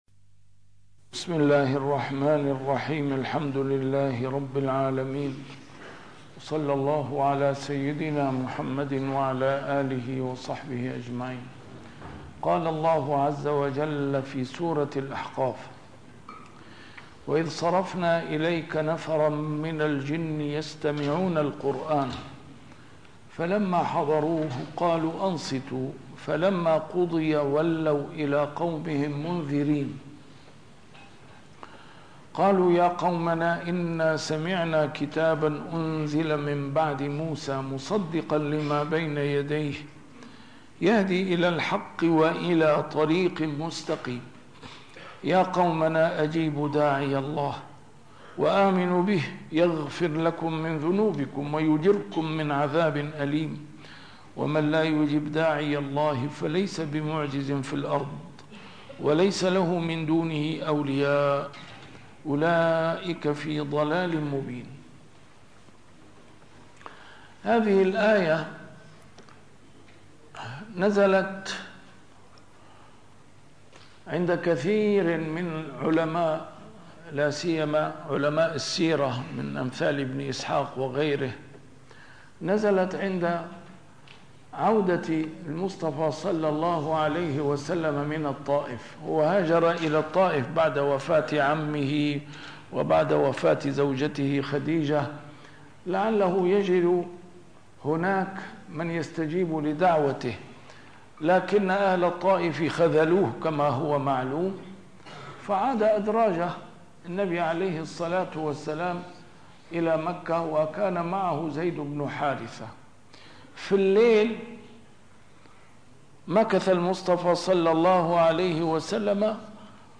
A MARTYR SCHOLAR: IMAM MUHAMMAD SAEED RAMADAN AL-BOUTI - الدروس العلمية - تفسير القرآن الكريم - تسجيل قديم - الدرس 629: الأحقاف 29-32